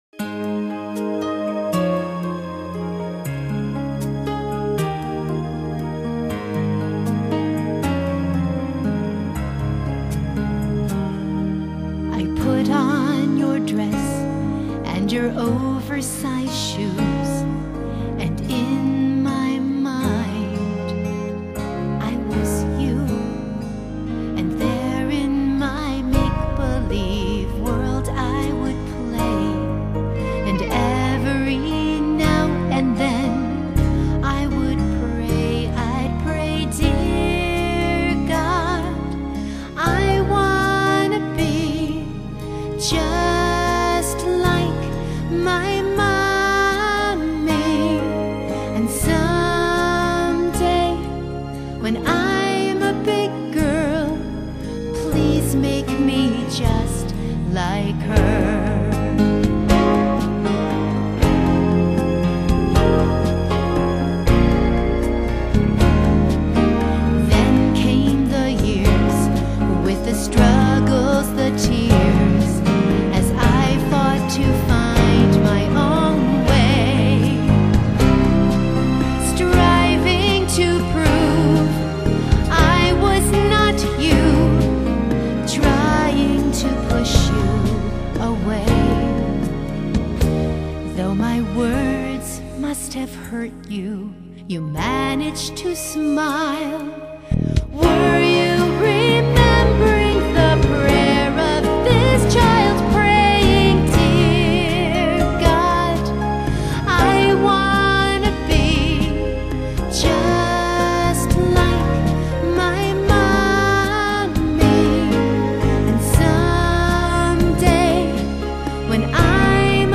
original studio recording
forgive the Disney Princess sound